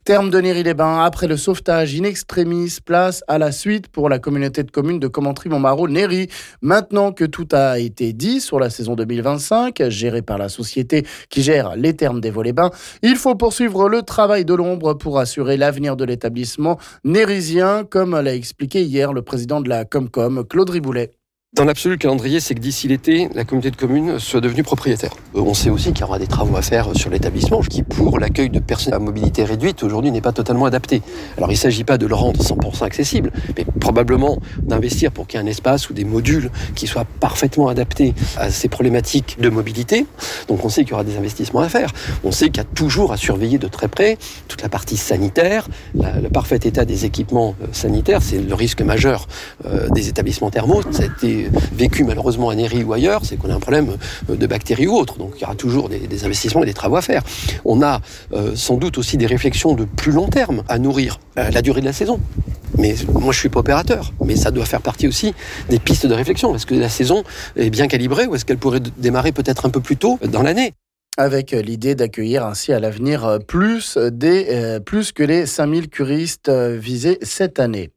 Maintenant que les Thermes de Néris-les-Bains ont bien été reprises en main niveau gestion pour cette saison, le travail de l’ombre va se poursuivre pour l’avenir de l’établissement : la ComCom de Commentry/Montmarault/Néris vise une acquisition du domaine d’ici l’été, et réfléchit déjà aux travaux nécessaires, niveau sanitaires et accessibilité, ainsi qu’une nouvelle durée de la saison...le président de la Comcom Claude Riboulet nous en parle...